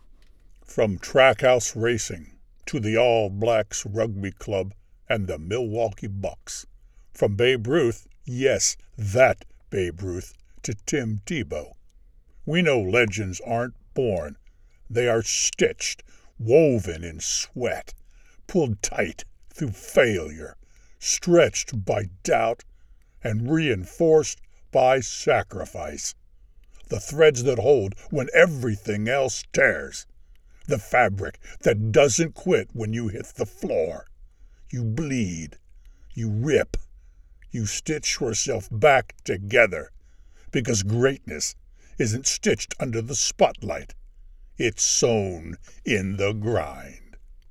Demos
VO sample
Senior